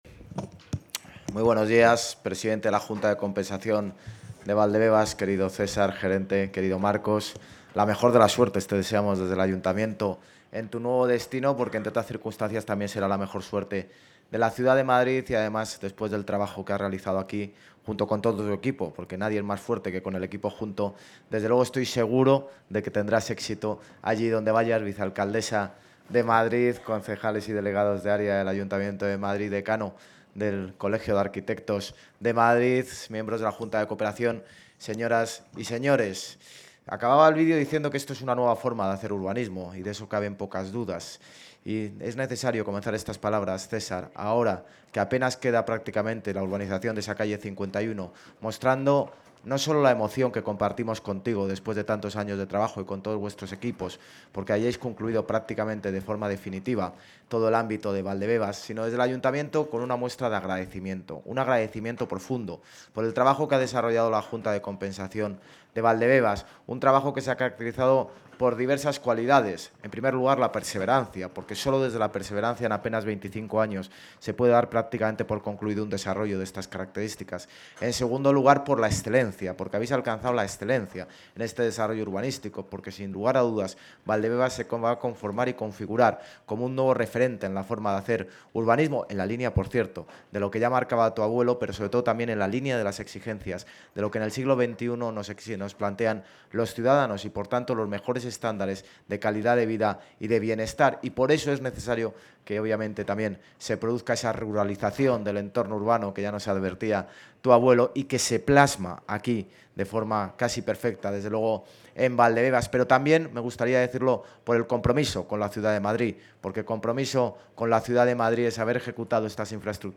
Nueva ventana:José Luis Martínez-Almeida, alcalde de Madrid
(AUDIO) INTERNECIÓN ALCADE INAGURACIÓN PARQUE PRINCESA LEONOR.mp3